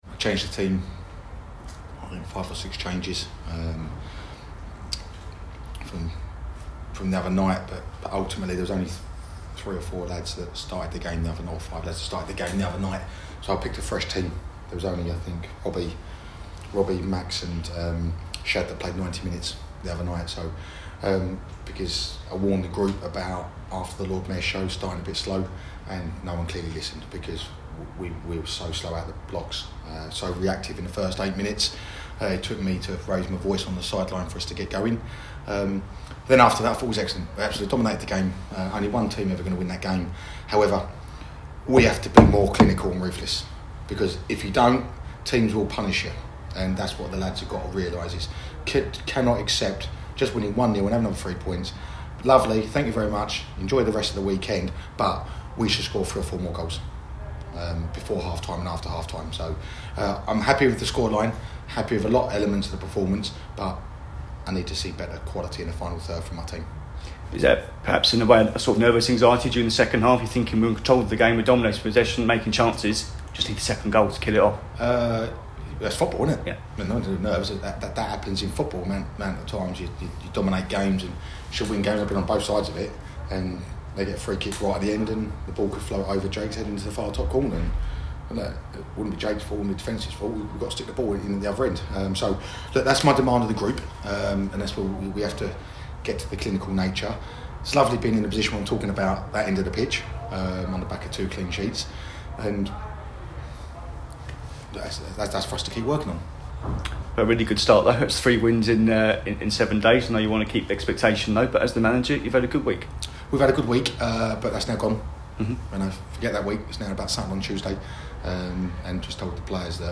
LISTEN: Gillingham manager Neil Harris spoke to us after their 1-0 victory over Accrington Stanley - 13/08/2023